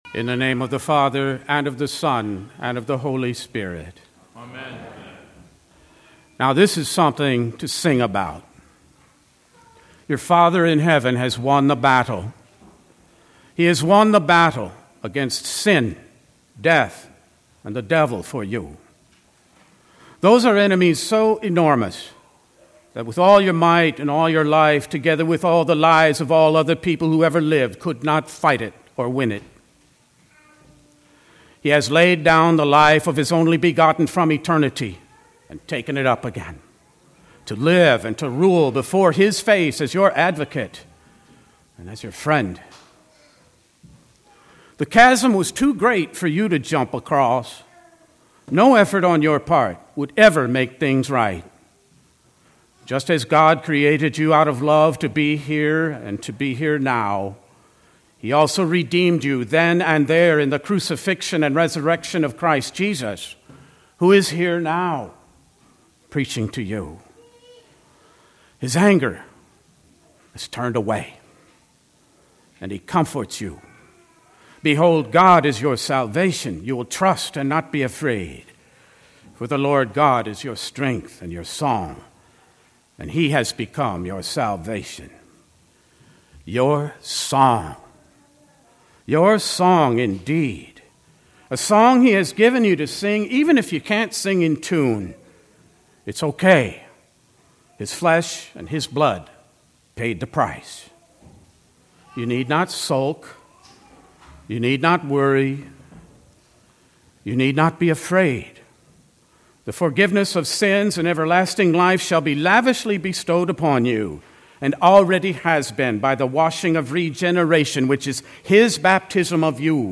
Sunday, April 28, 2024 (Cantate: Easter V) - Sermon